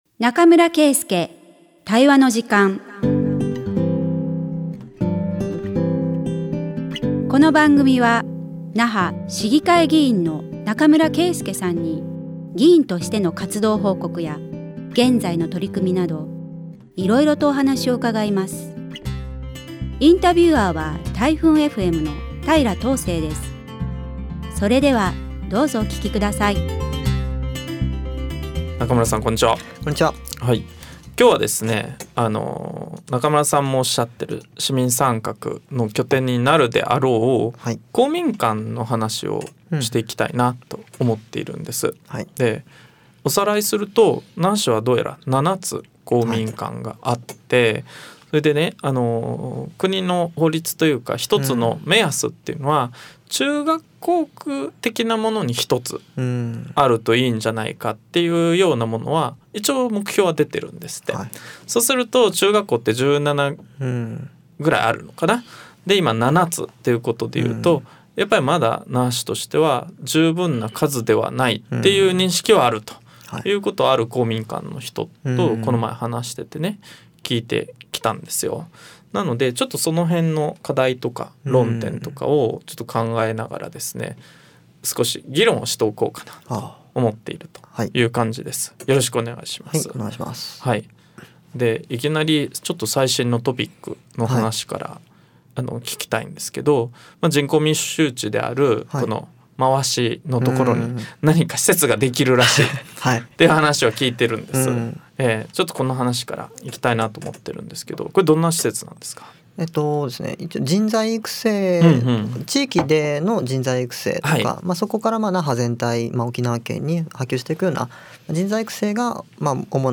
那覇市議会議員の中村圭介さんに、議員としての活動報告や、現在の取組みなどお話を伺います。